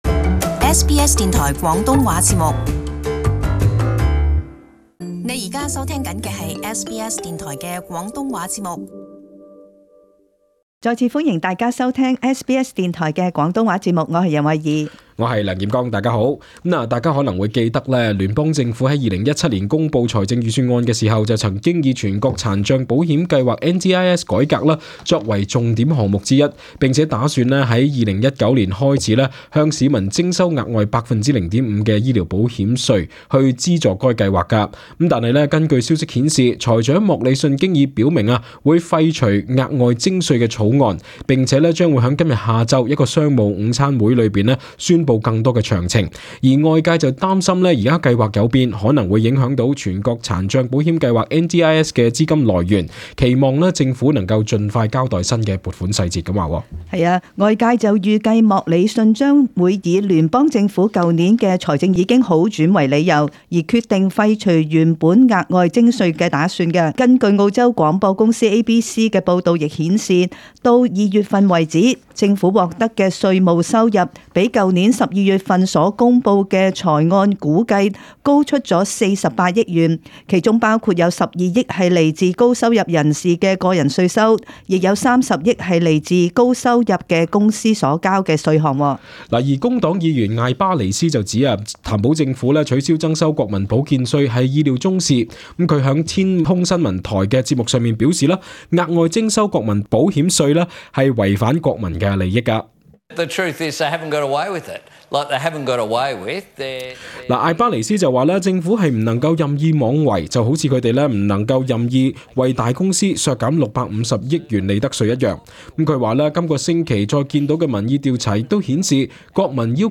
【時事報導】財長證實會放棄增稅以資助NDIS草案